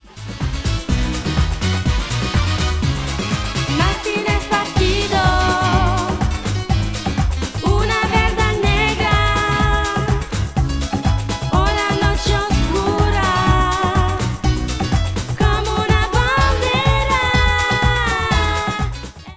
remixé
en version merengué